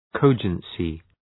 Προφορά
{‘kəʋdʒənsı}
cogency.mp3